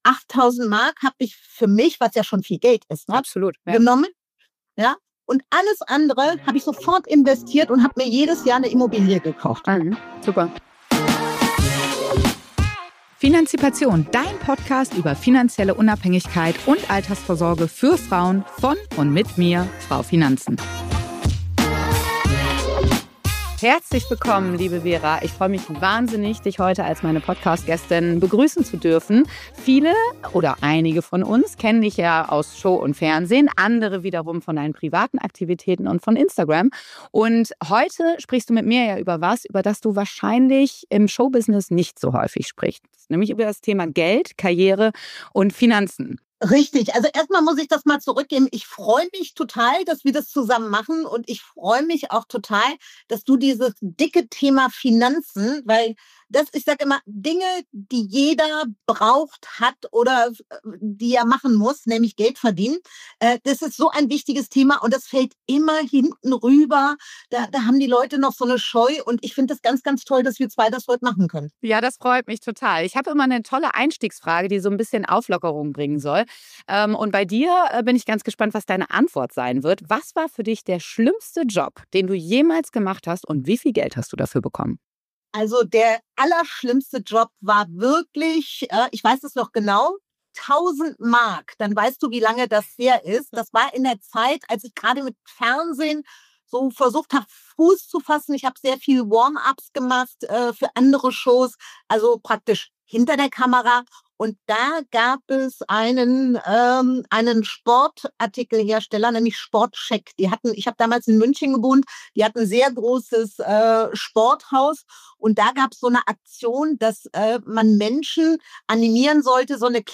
In dieser Episode sprechen wir mit Vera Int-Veen, bekannt aus Fernsehen und Instagram, über ihren Weg im Showbusiness und ihren Umgang mit Geld und Finanzen.